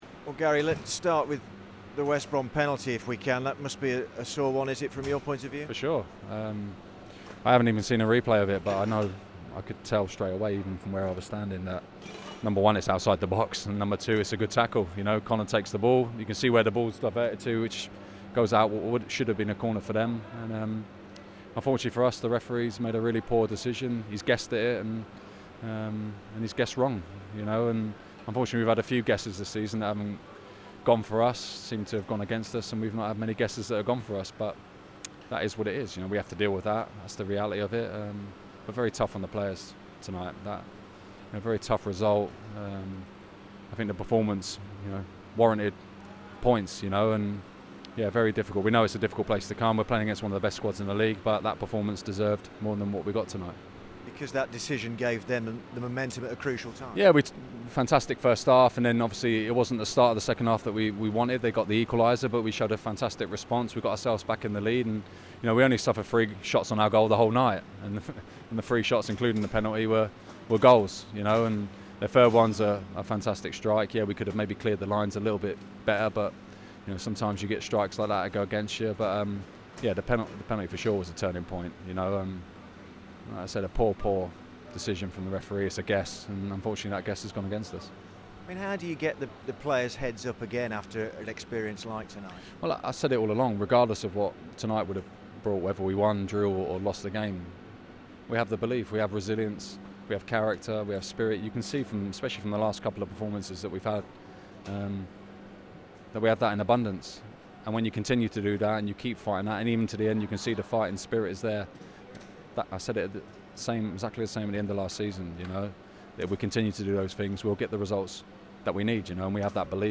The Blues boss talks to BBC WM following defeat at The Hawthorns.